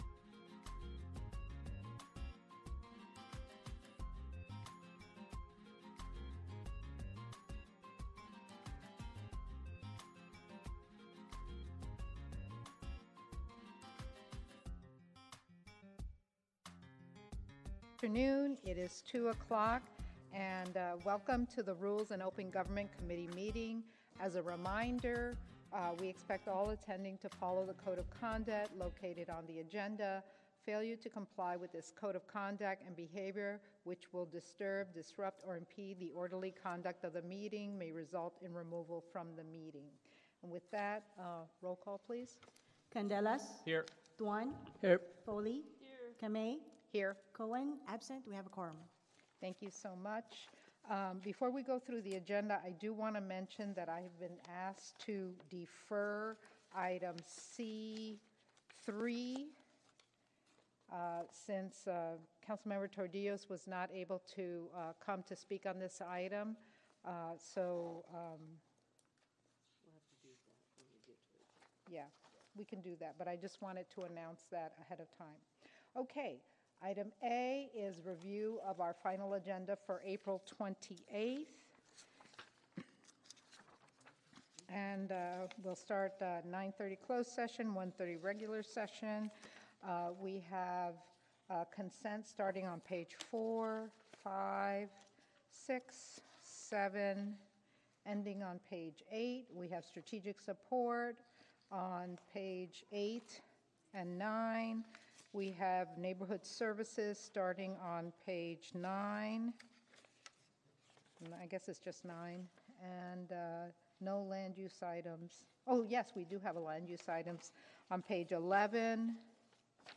You'll hear authentic audio capturing the voices of city officials, community leaders, and residents as they grapple with the local issues of the day.
Tuning in, you become a fly on the wall in council chambers and civic spaces, gaining insight into the complexities of urban management and community decision-making.